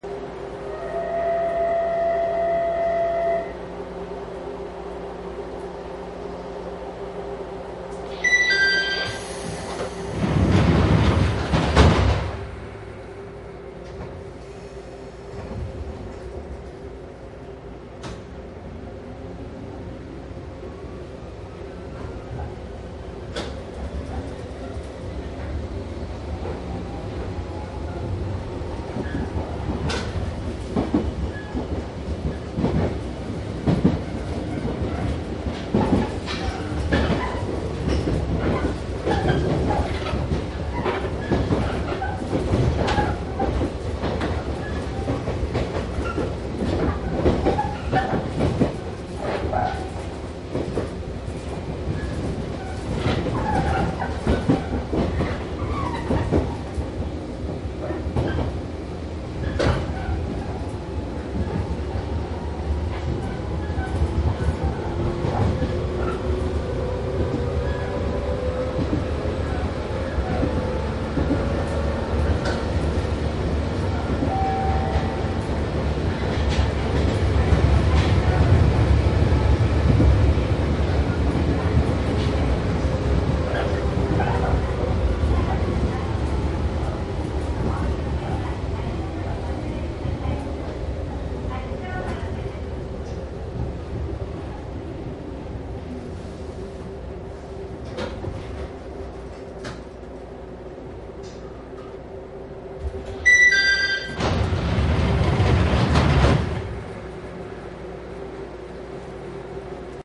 JR鶴見線103系・南武支線101系走行CD
鶴見線と南武支線で103系と101系を録音しました。
運転手がハンドル操作をするとエアーの抜ける音がしてコンプレッサーが回りだします。そういう音が聞き手取れます。運転席側の台車で録音すると前位側の床下に断流器がついてるので床下の箱から火花が出る音が聞こえます。
いずれもマイクECM959です。TCD100の通常SPモードで録音。商品はオリジナルCDR版鉄道走行音CDです。
実際に乗客が居る車内で録音しています。貸切ではありませんので乗客の会話やが全くないわけではありません。